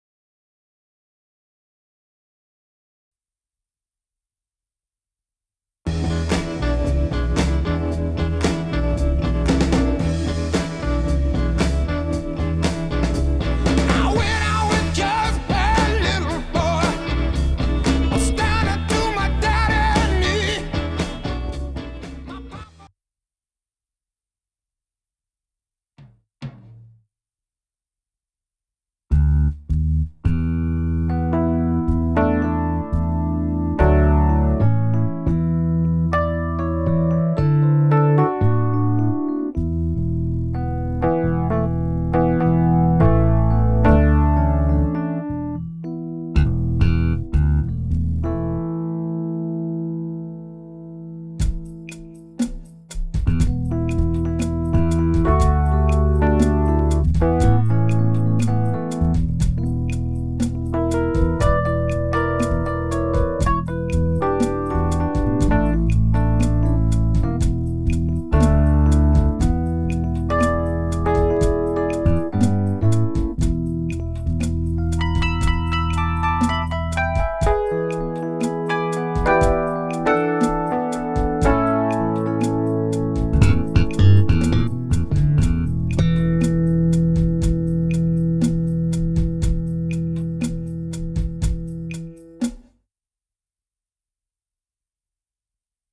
The chain is a bit of a delay, yet I play this piece live: